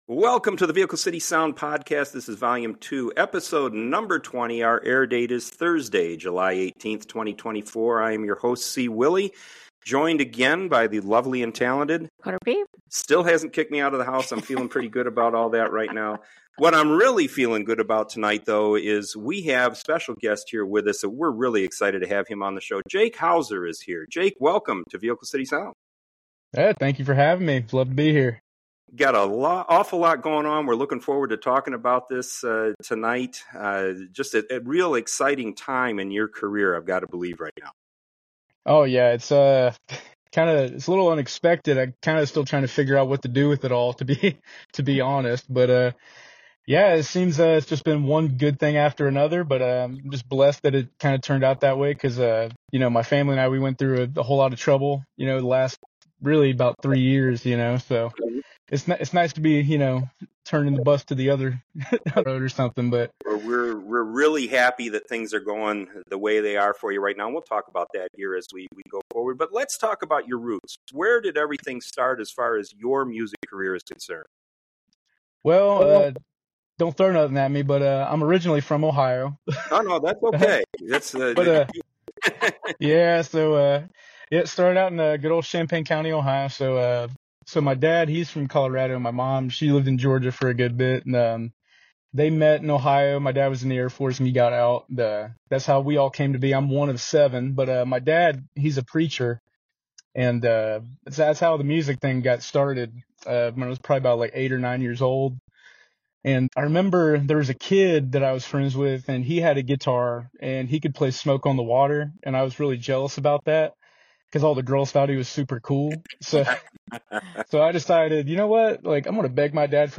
You'll love his story telling, and his music is pure country!&nbsp